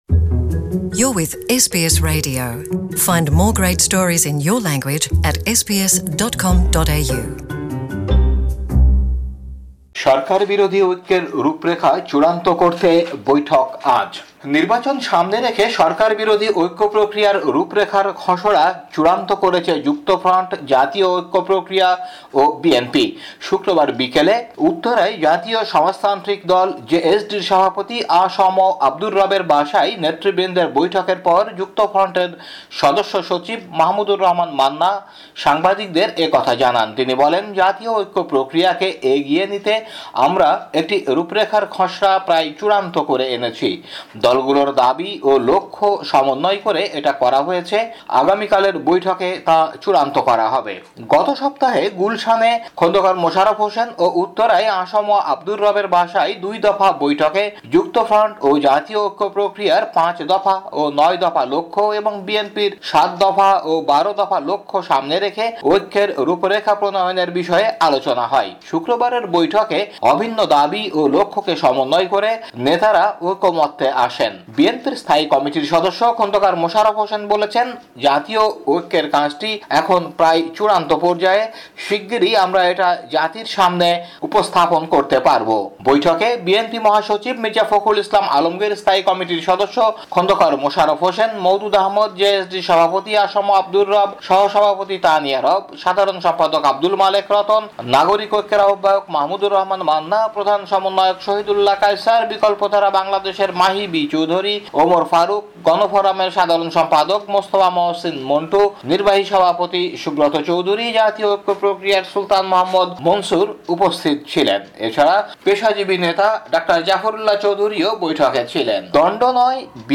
বাংলাদেশী সংবাদ বিশ্লেষণ: ১৩ অক্টোবর ২০১৮